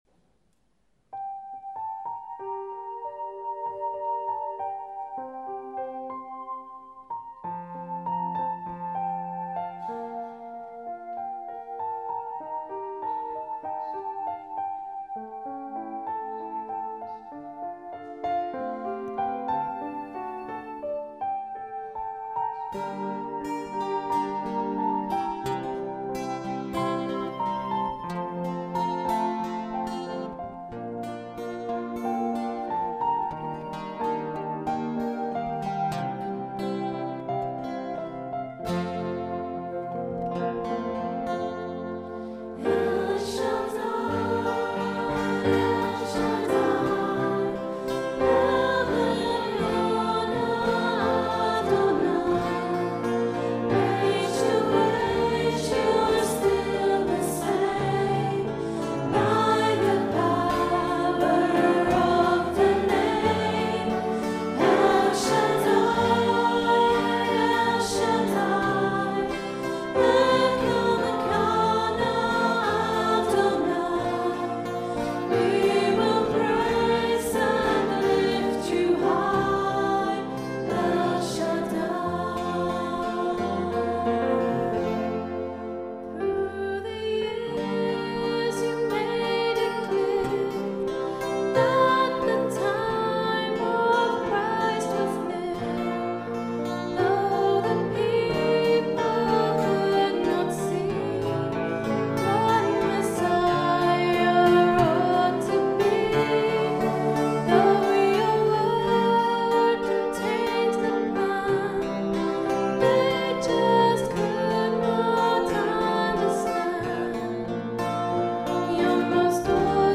Recorded on a Zoom H4 digital stereo recorder at Maundy Thursday Mass 21st April 2011.
A very popular contemporary Christian song.